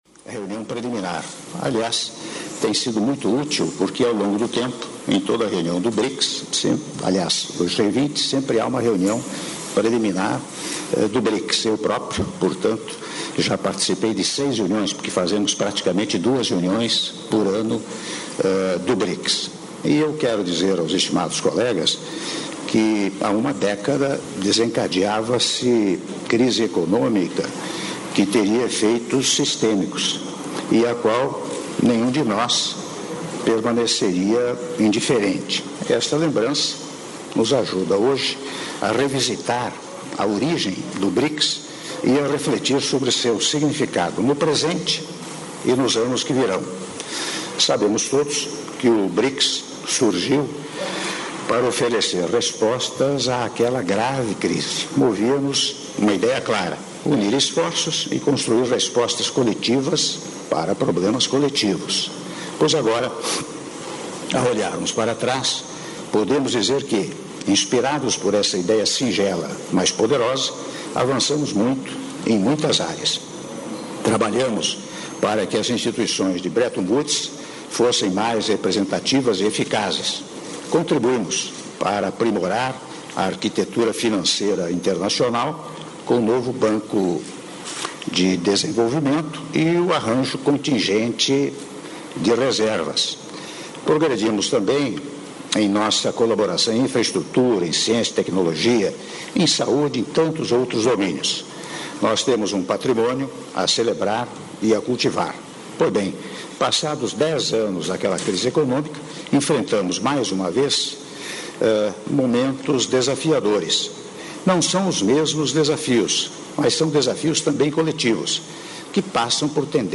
Áudio do discurso do Presidente da República, Michel Temer, durante reunião com Líderes do BRICS - Buenos Aires/Argentina (7min30s)